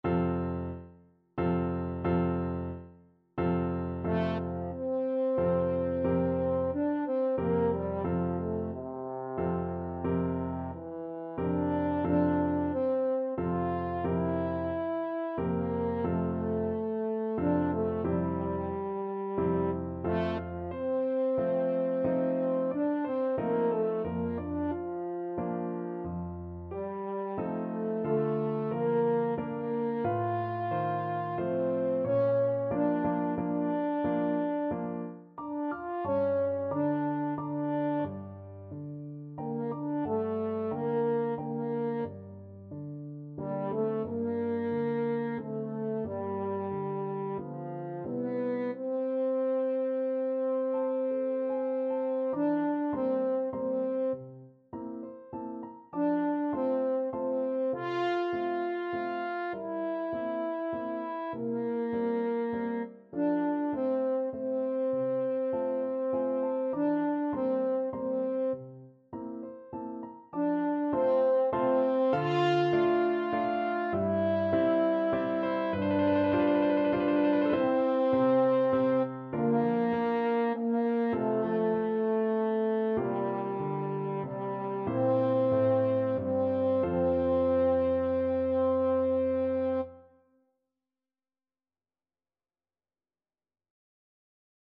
French Horn
3/4 (View more 3/4 Music)
F major (Sounding Pitch) C major (French Horn in F) (View more F major Music for French Horn )
~ = 90 Allegretto moderato
Classical (View more Classical French Horn Music)